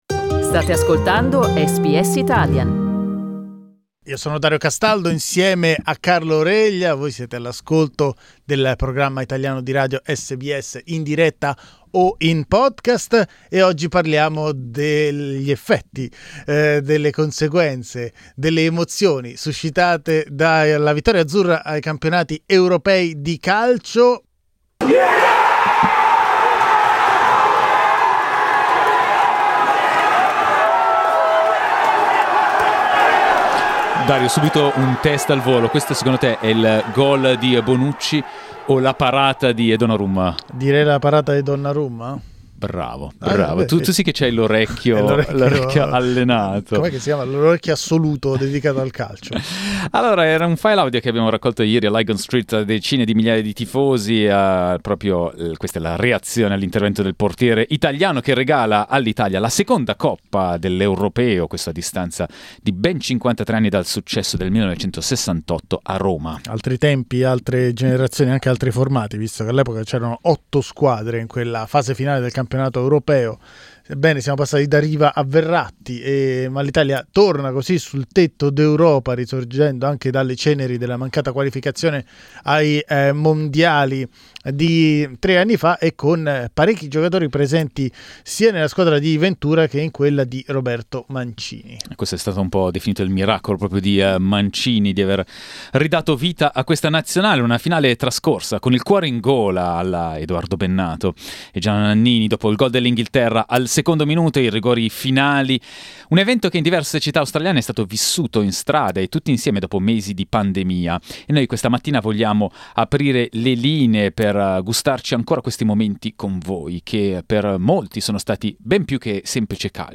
Gli ascoltatori e le ascoltatrici di SBS Italian si raccontano ad un giorno dall'impresa degli Azzurri.